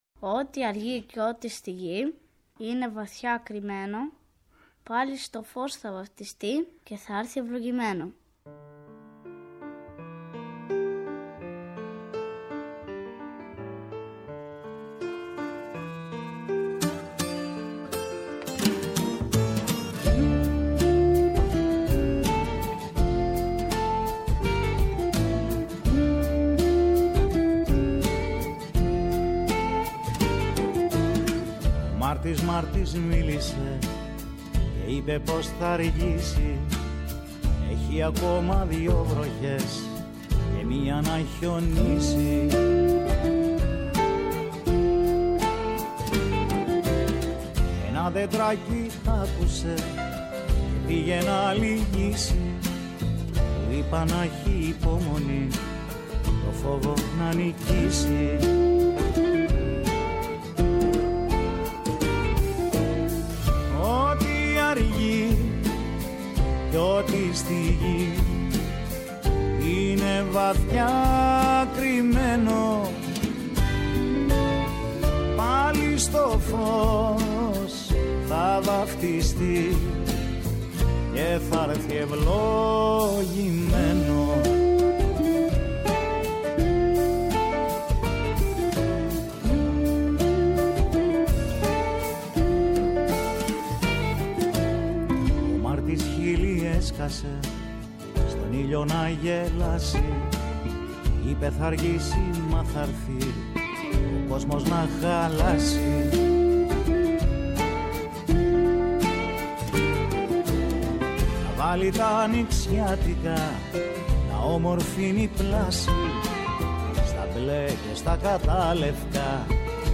Κάθε Παρασκευή 11:00-12:00 , στο Πρώτο Πρόγραμμα της Ελληνικής Ραδιοφωνίας. «Με τούτα και μ’ εκείνα», τελειώνει η εβδομάδα τις καθημερινές.